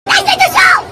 explode4.ogg